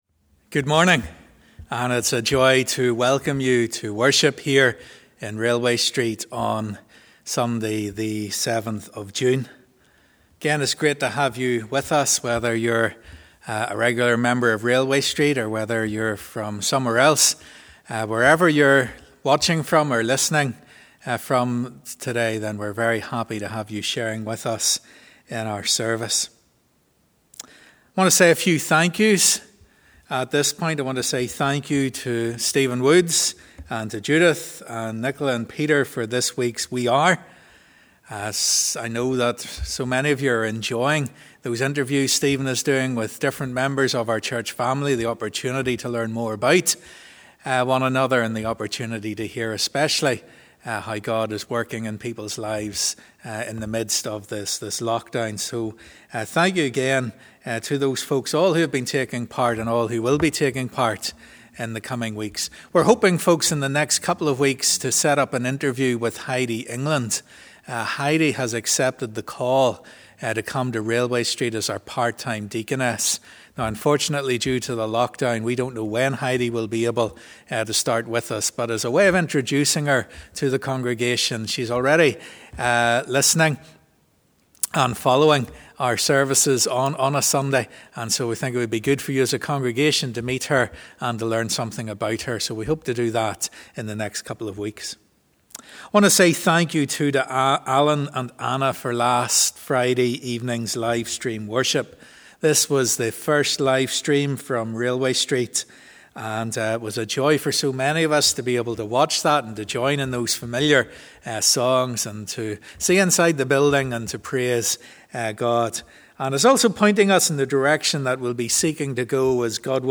As we come to worship we join in the words of '10,000 Reasons (Bless the Lord)'.
As we conclude let’s use the song 'Holy Spirit Living Breath of God'.